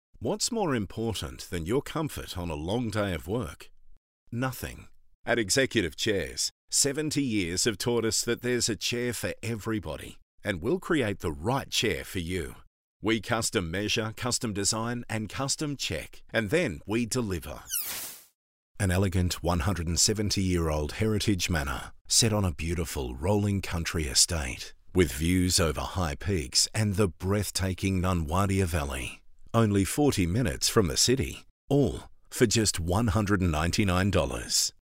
• Soft Sell
• Neumann TLM103 / Rode NT2a / Sennheiser MKH416